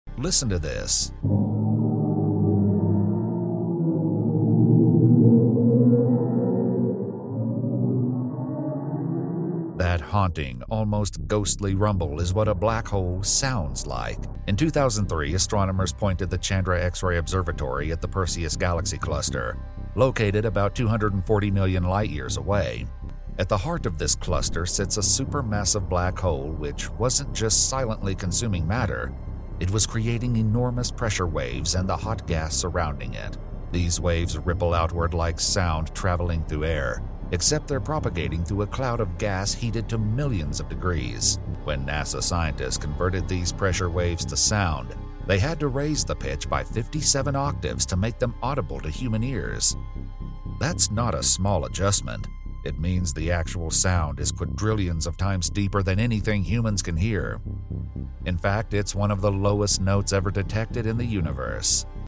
This sound is caused by the black hole in the Perseus cluster.